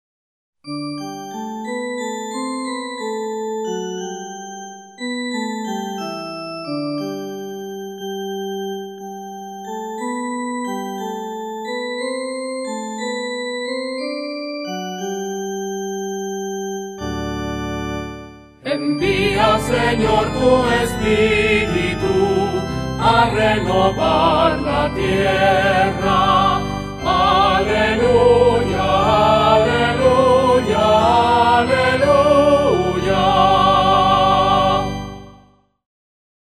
SALMO RESPONSORIAL Del salmo 103 R. Envía, Señor, tu Espíritu a renovar la tierra.